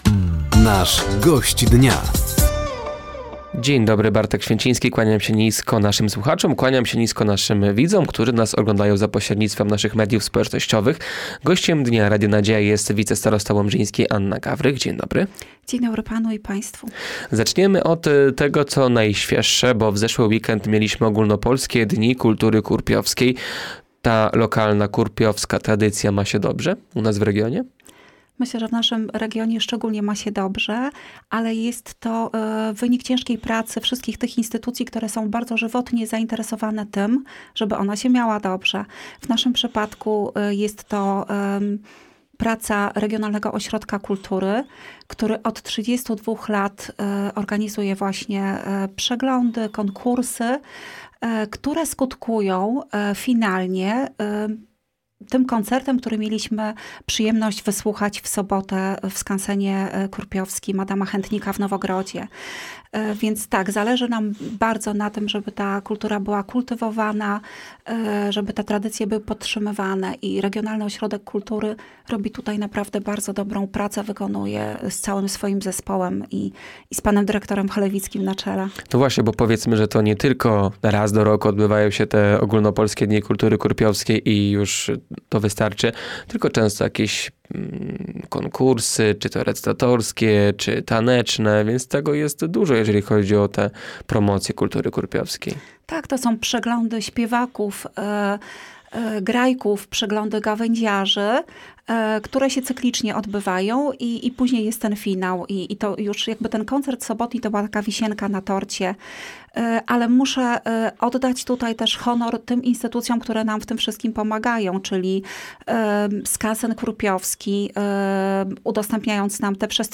Gościem Dnia Radia Nadzieja była Anna Gawrych, wicestarosta łomżyński. Tematem rozmowy były między innymi Ogólnopolskie Dni Kultury Kurpiowskiej w Nowogrodzie, nabór na magazyny energii, stypendia starosty łomżyńskiego oraz remont mostu.